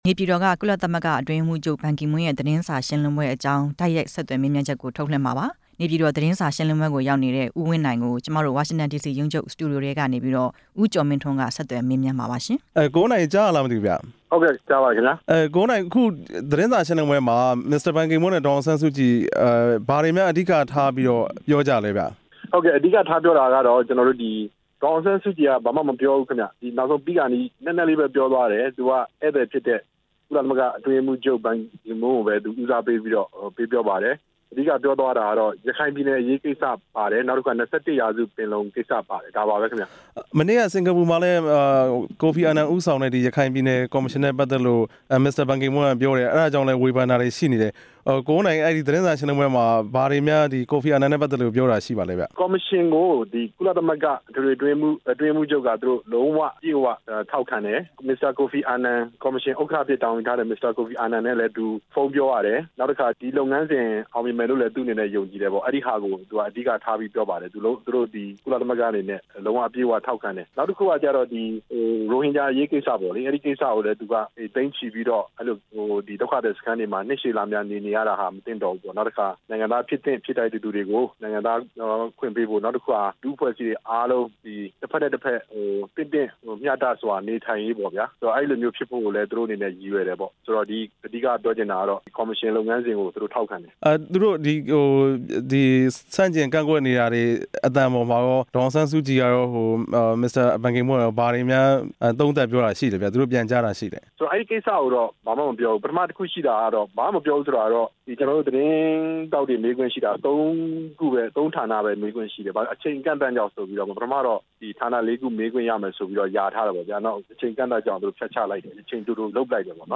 ကုလအတွင်းရေးမှူးချုပ်ရဲ့ သတင်းစာရှင်းလင်းပွဲအကြောင်း တိုက်ရိုက်မေးမြန်းချက်